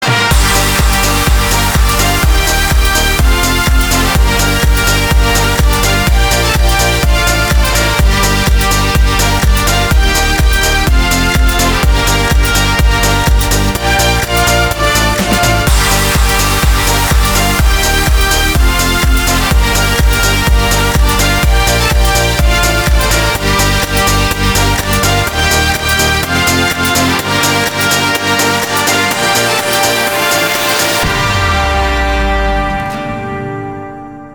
громкие
без слов
house